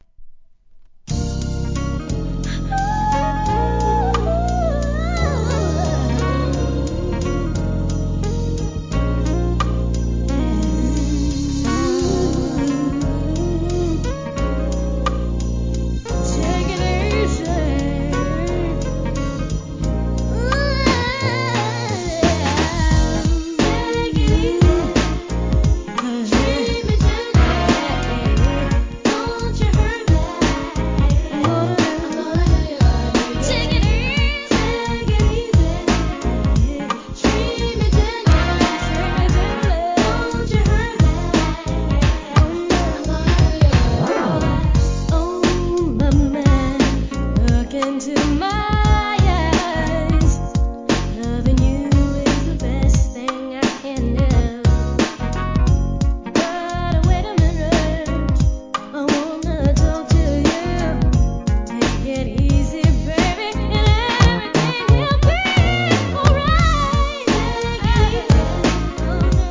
HIP HOP/R&B
1994年のNICE哀愁ミディアムR&B!!